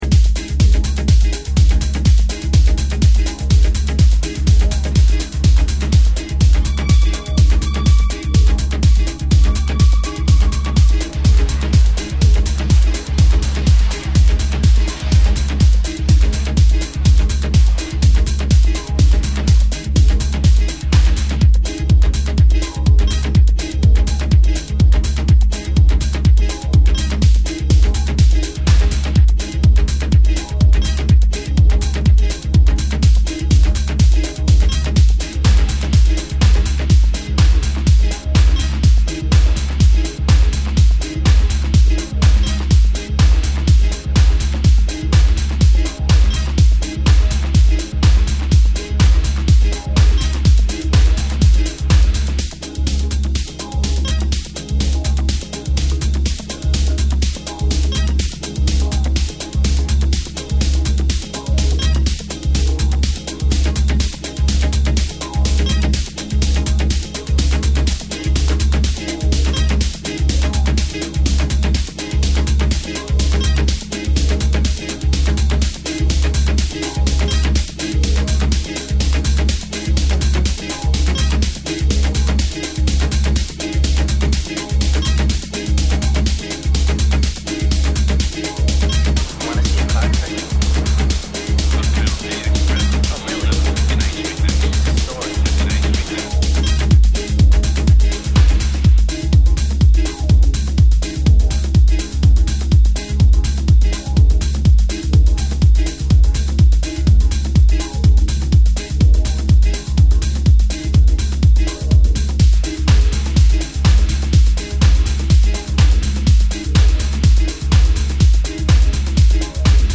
special and unique signature of funk and soul!